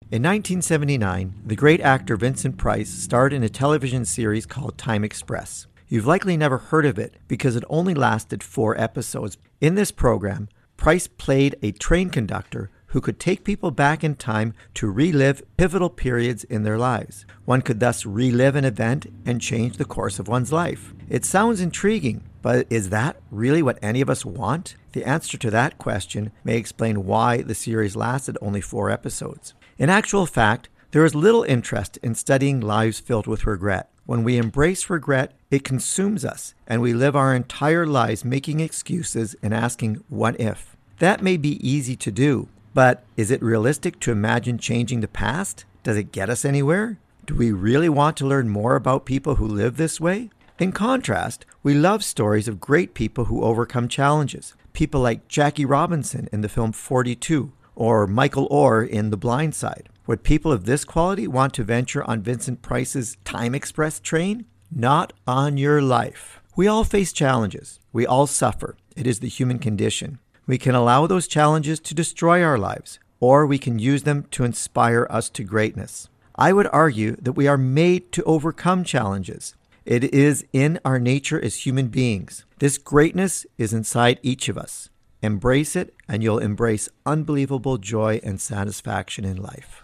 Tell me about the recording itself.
Recording Location: CFIS-FM, Prince George